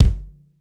• Loud Bass Drum Sound G Key 738.wav
Royality free bass drum single shot tuned to the G note. Loudest frequency: 340Hz
loud-bass-drum-sound-g-key-738-QJK.wav